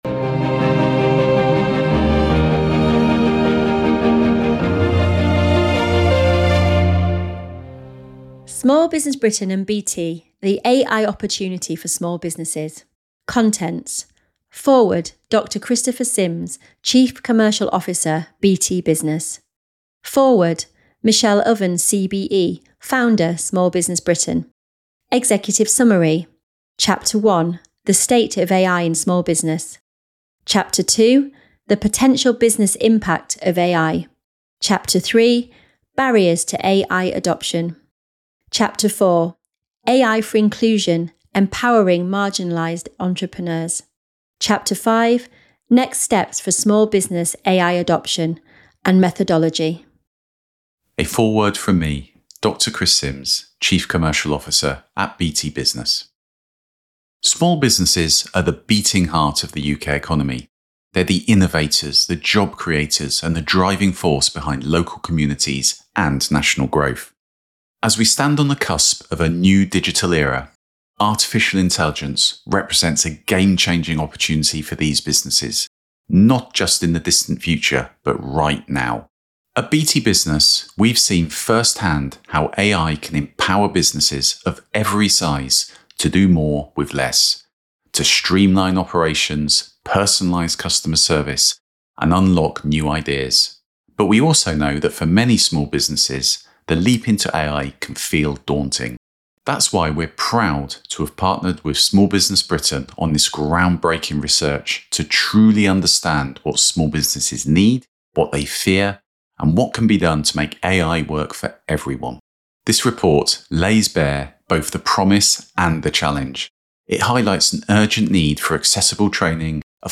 Download Report Play Audiobook A huge thanks to BT Business for partnering with us on this report.